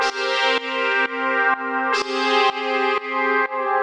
GnS_Pad-MiscA1:4_125-C.wav